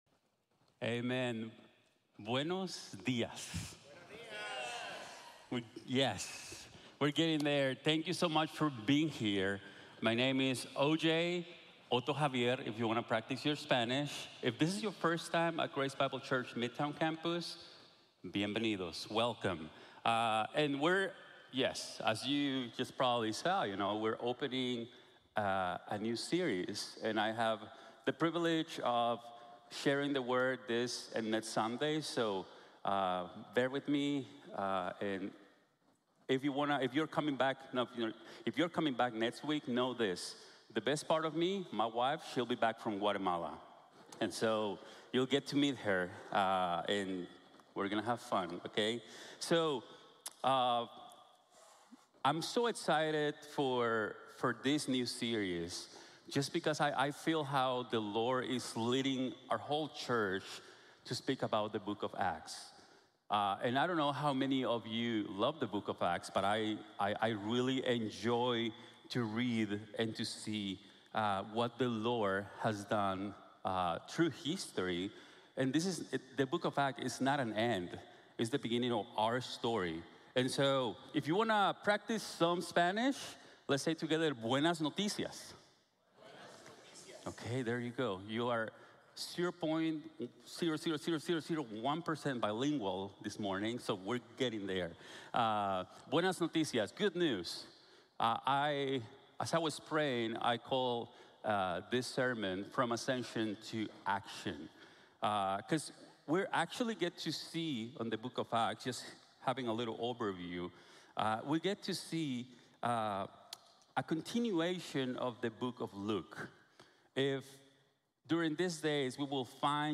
From Ascension to action | Sermon | Grace Bible Church